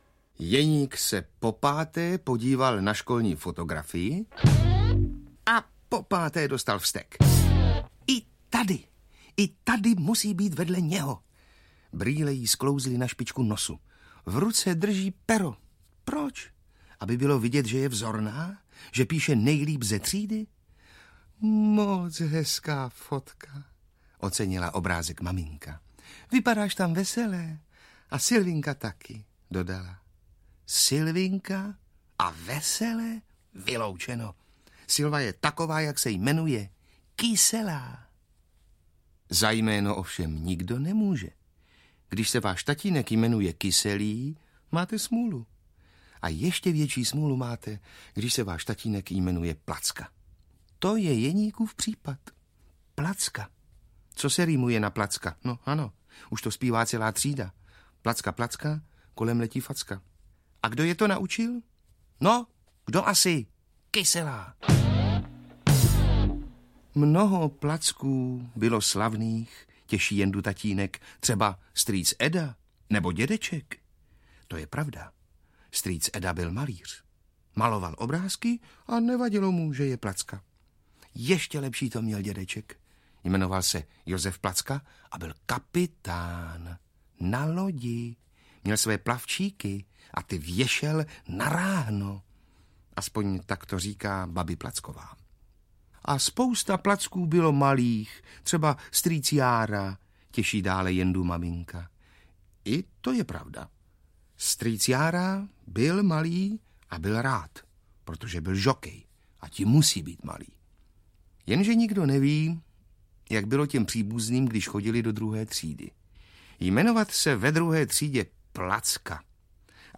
Sísa Kyselá audiokniha
Ukázka z knihy